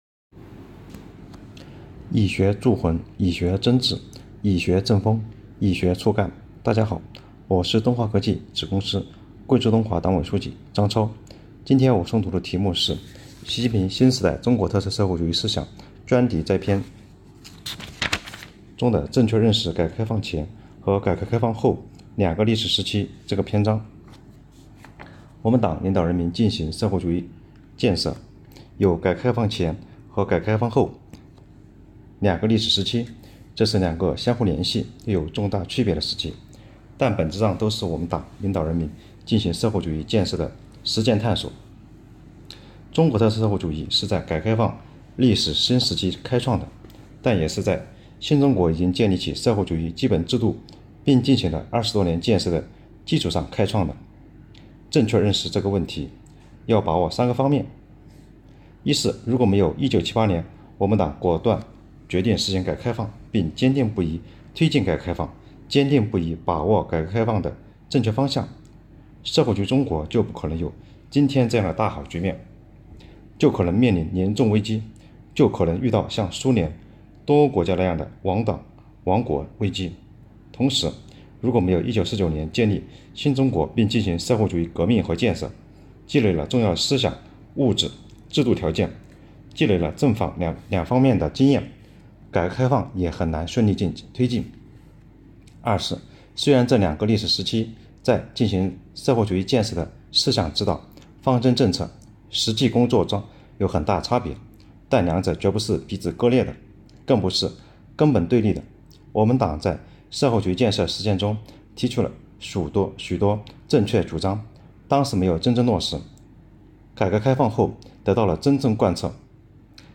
主题教育 | “线上读书班”，今天听我读（1）